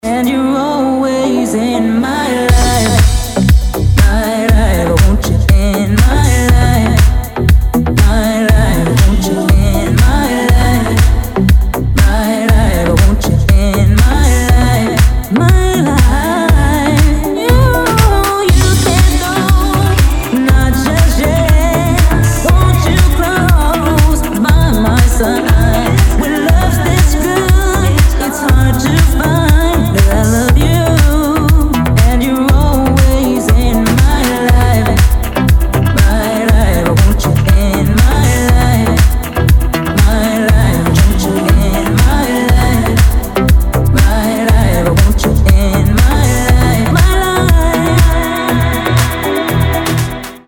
• Качество: 320, Stereo
мужской вокал
deep house
dance
club
vocal